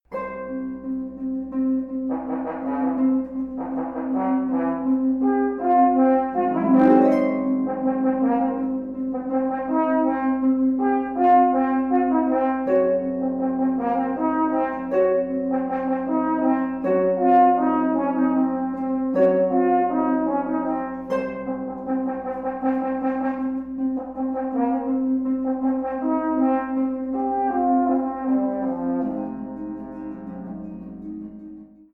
The result is a beautiful, atmospheric piece. It is tonal/modal, with hints of impressionism.
In Arkadia for Horn and Harp, Gary Schocker (b. 1959)
Both recordings are live and unedited, although I added a small amount of reverb to the files since we were not rehearsing in a concert hall.